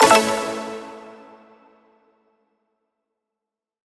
button-solo-select.wav